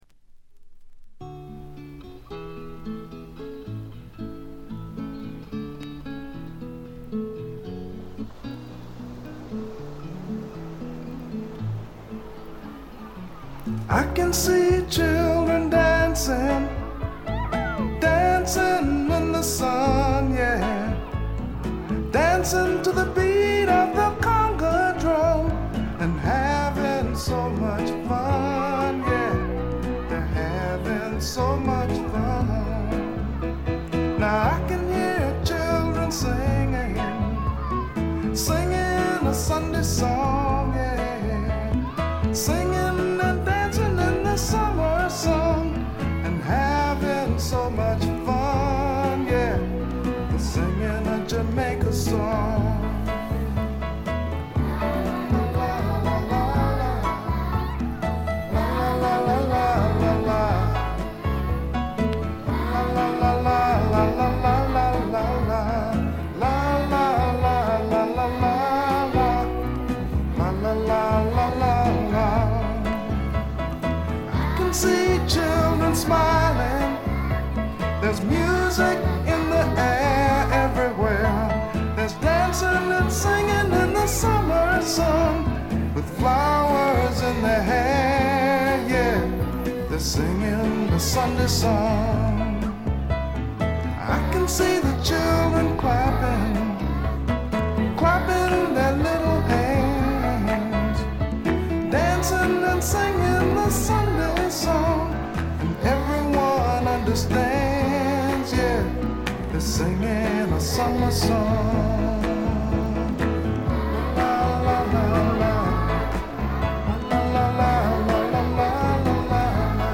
これ以外はところどころで軽微なチリプチが少々。
じわーっと沁みてくるとても浸透力のある声の魅力が本作では全開です。
フォーキーなアコースティック・グルーヴが胸を打つ名盤。
試聴曲は現品からの取り込み音源です。